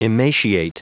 Prononciation du mot emaciate en anglais (fichier audio)
emaciate.wav